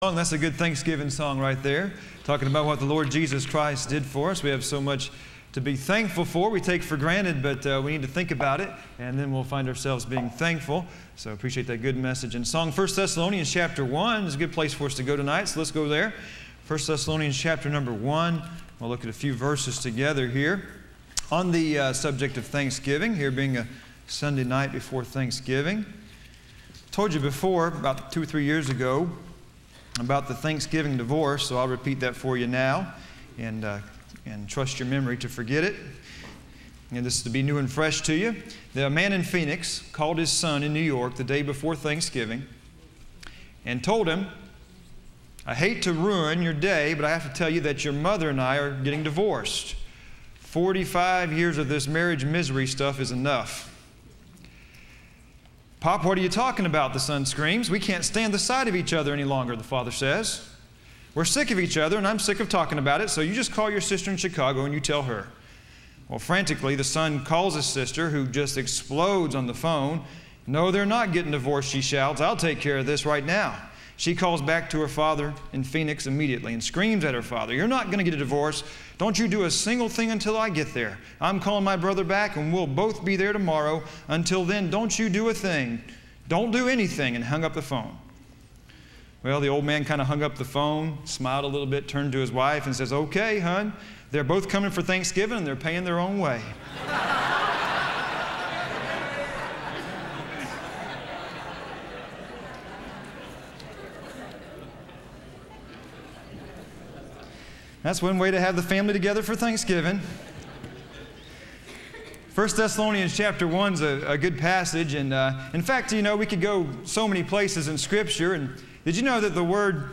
Listen to Message
Service Type: Sunday Evening